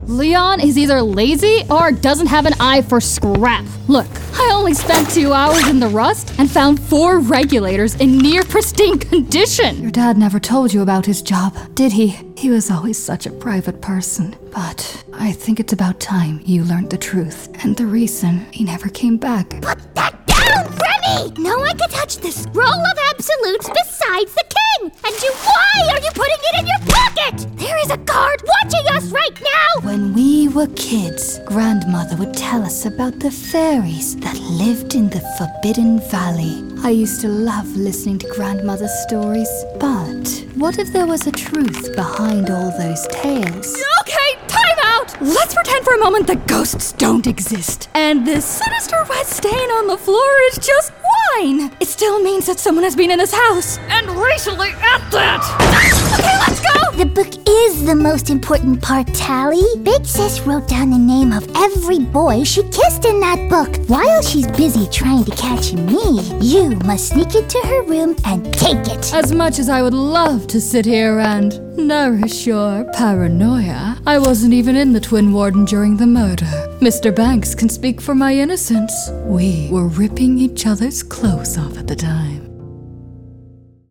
Professional Broadcast Quality home studio
Voice Age
20s/30s, 30s/40s
Accents
American, Irish Northern Irish